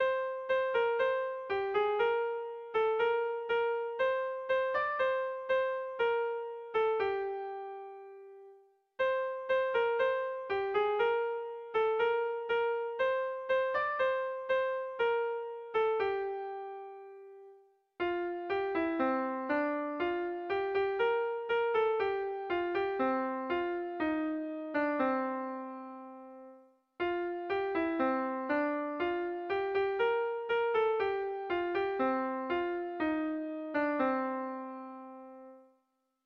Melodías de bertsos - Ver ficha   Más información sobre esta sección
Doinu ederra.
Zortziko handia (hg) / Lau puntuko handia (ip)
AABB